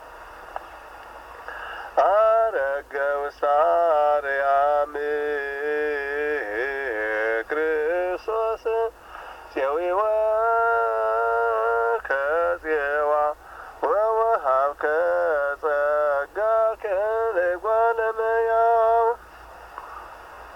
Index of /churchmusic/zema timehert bet/mahlet yared/10 mewaseat/5 mewaseat emyohannes eske yohannes/57 zebeale erget